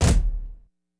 Waka SNARE ROLL PATTERN (86).wav